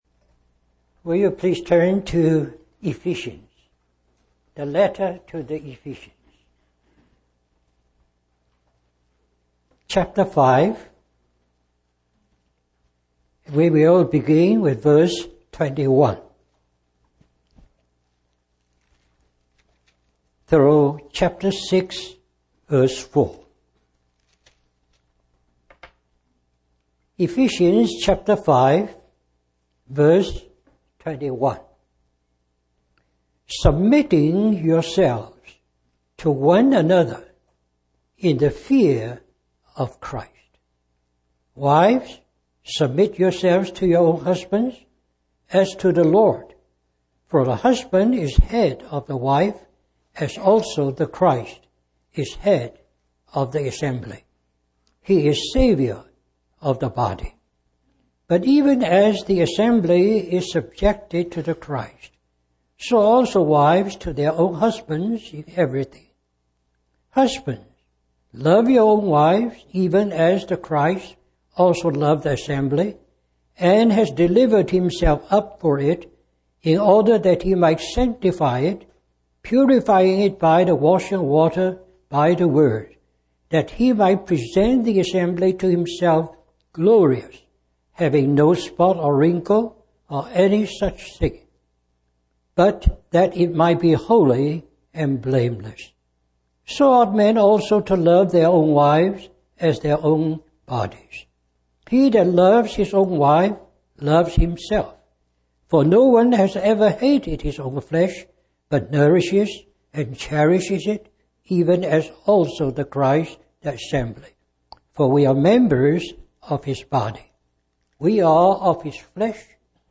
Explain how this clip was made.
Richmond, Virginia, US